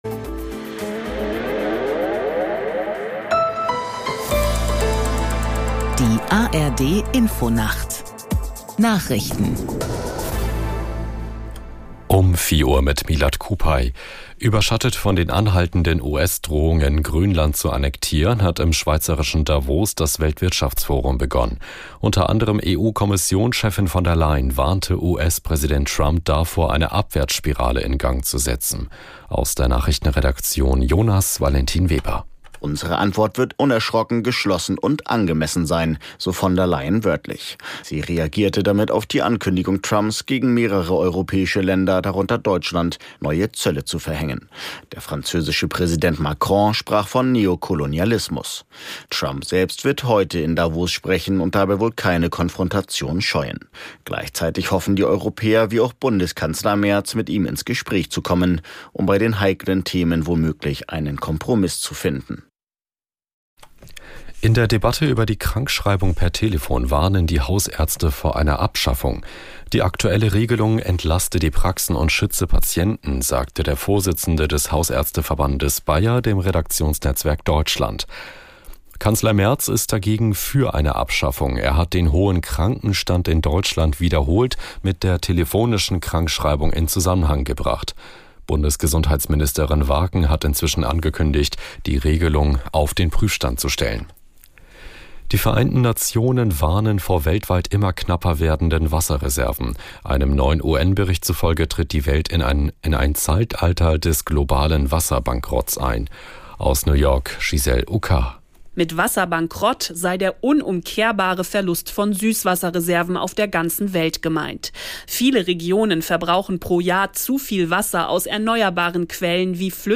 Genres: Daily News, News